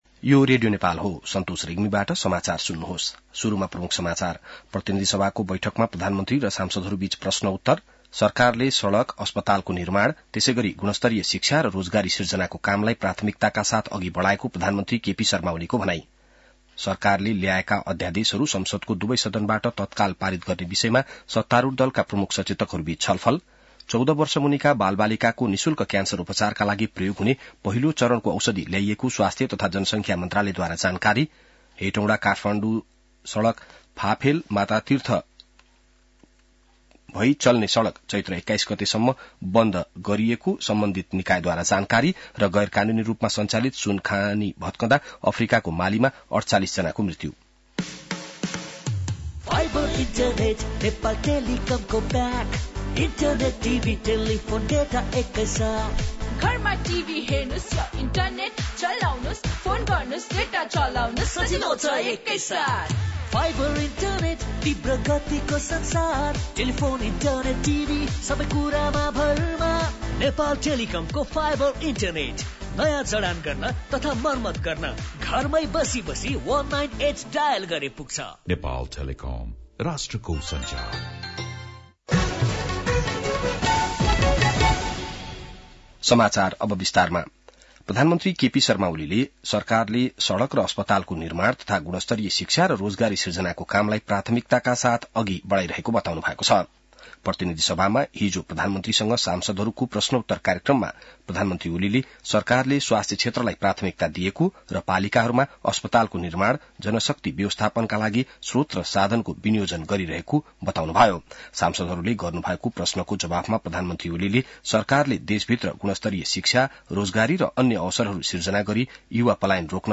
बिहान ७ बजेको नेपाली समाचार : ६ फागुन , २०८१